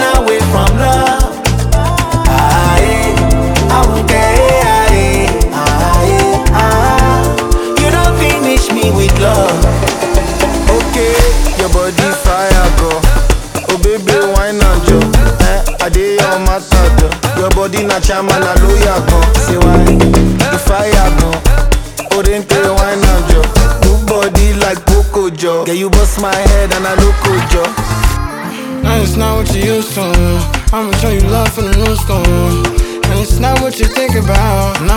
Жанр: Африканская музыка